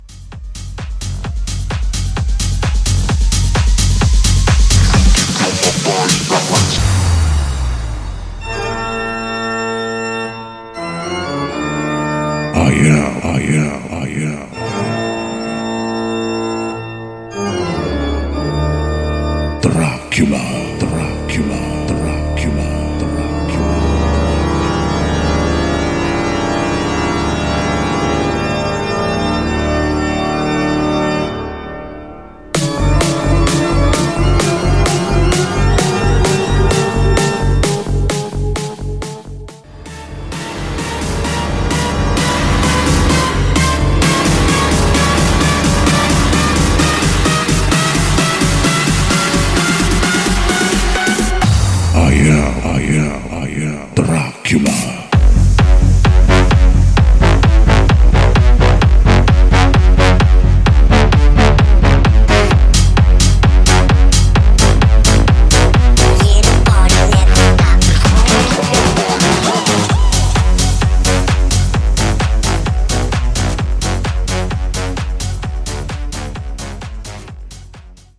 Genre: HIPHOP
Dirty BPM: 103 Time